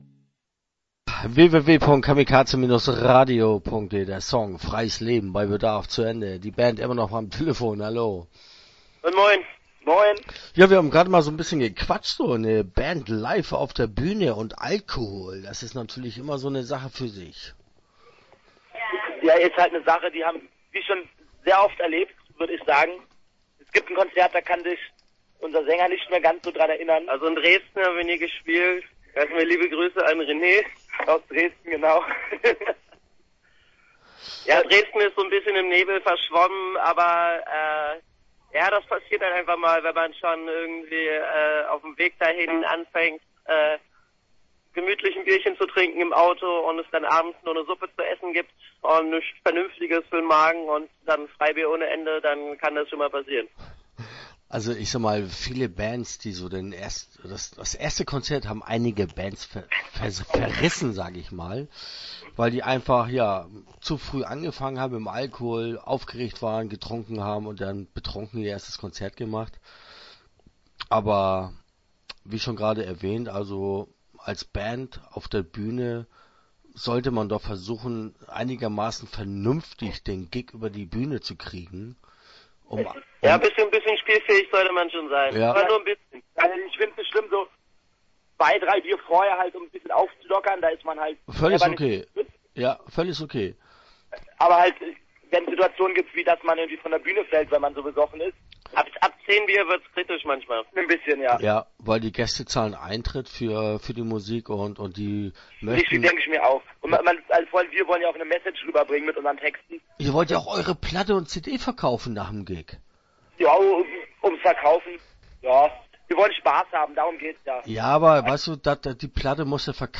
Bei Bedarf - Interview Teil 1 (9:35)